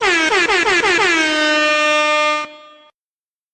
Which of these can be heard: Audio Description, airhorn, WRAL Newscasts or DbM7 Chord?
airhorn